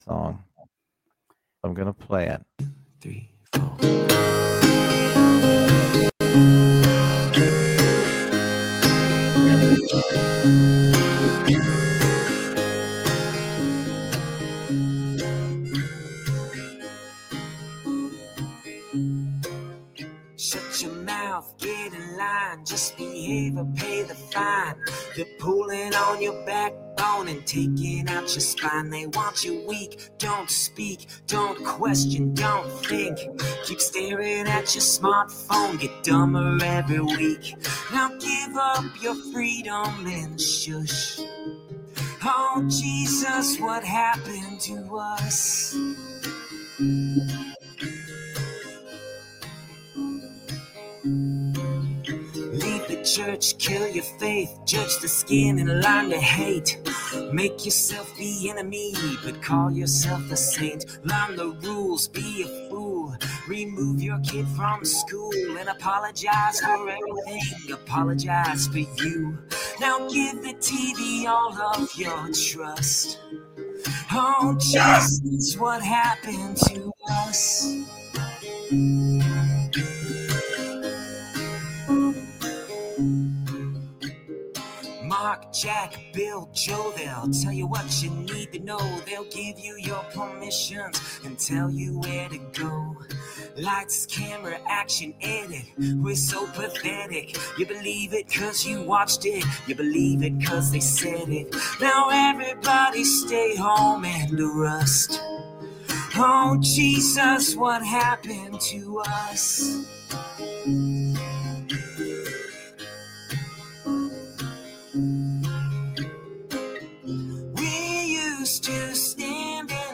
Fakeologist show Live Sun-Thu 830pm-900pm EDT